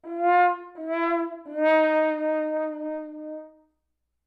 horn_fail_wahwah_2
fail fail-sound french-horn horn lose sad sad-trombone trombone sound effect free sound royalty free Memes